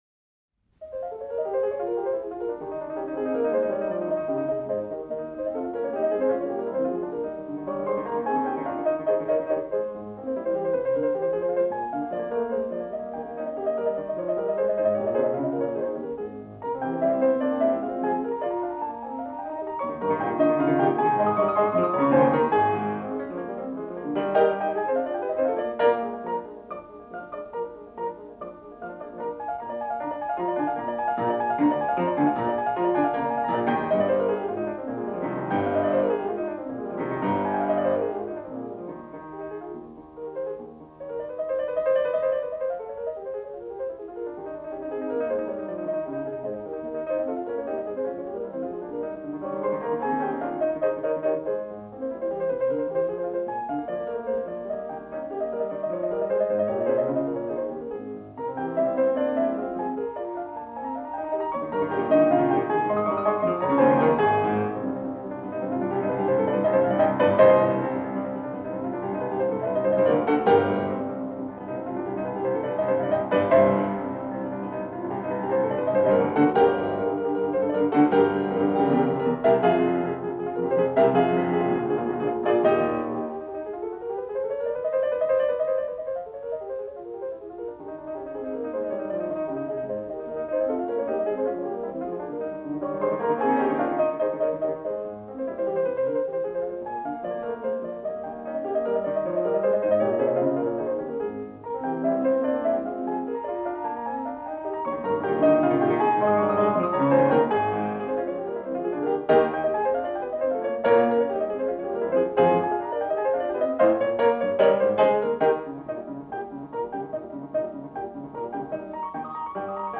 제4악장: 알레그로 (내림가장조)
론도 형식의 악장이다.[6] 끊임없이 움직이는 리듬과 론도 주제, 그리고 복대위법적인 전개가 특징이다.
론도 주제는 곧바로 복대위법적인 수법으로 반복되며, 뒤따르는 프레이즈도 마찬가지로 반복된다.
코다는 내림 A 음의 페달 포인트를 들으면서 론도 주제가 내려가고, 그대로 조용해져 피아니시모로 가만히 전곡을 마친다.